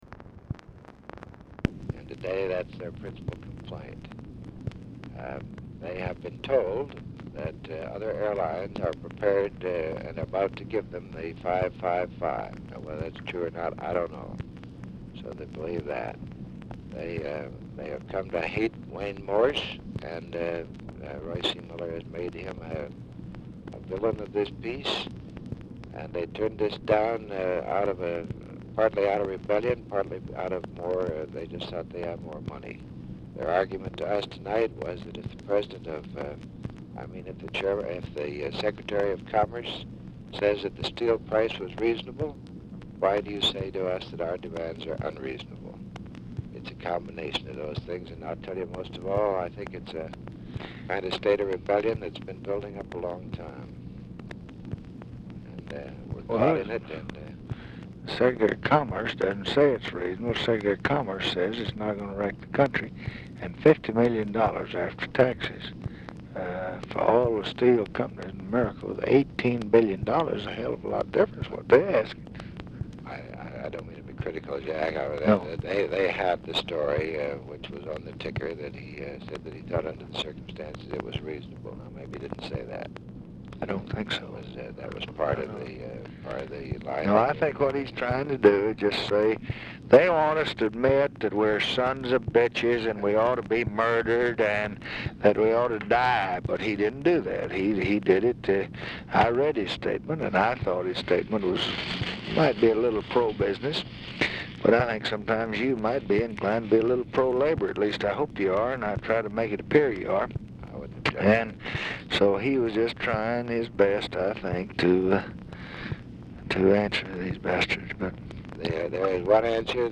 TV OR RADIO AUDIBLE IN BACKGROUND AT END OF CONVERSATION
Format Dictation belt
Specific Item Type Telephone conversation